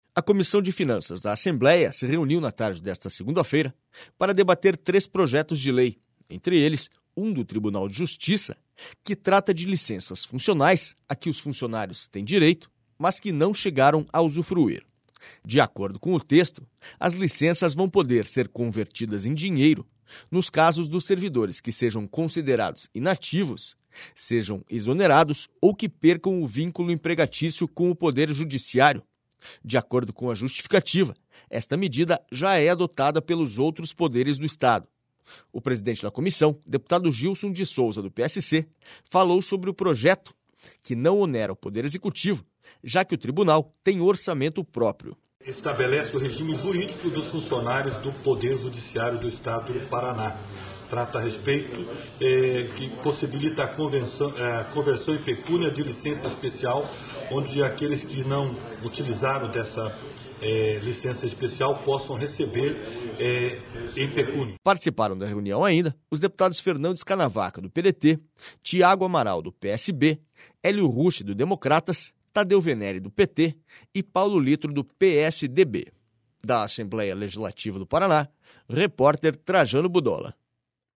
SONORA GILSON DE SOUZA